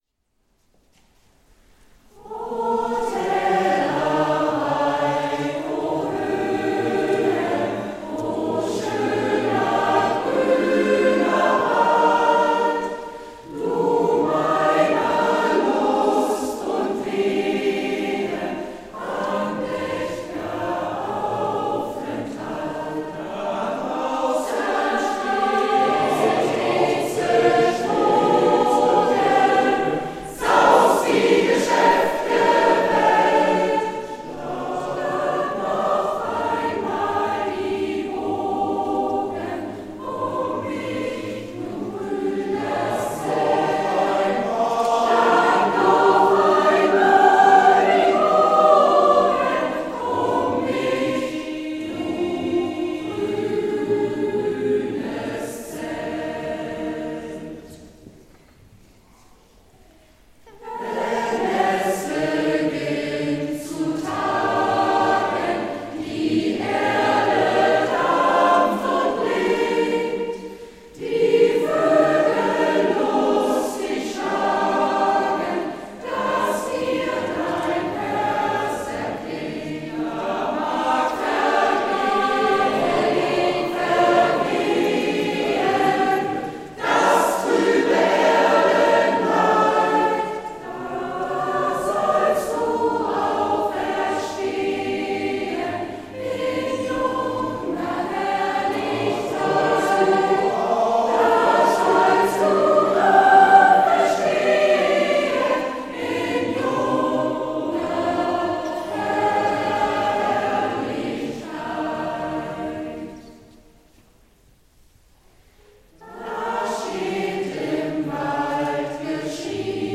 Jubiläumskonzert in St. Laurentii
Zu unserem Jubiläumskonzert am Dienstag, den 15.03.2016 in der Laurentii-Kirche waren das Gros unserer Schulgemeinschaft sowie Ehemalige und Freunde zusammengekommen, um gemeinsam einen stimmungsvollen und würdigen Einstieg in das Jubiläumsjahr zu begehen.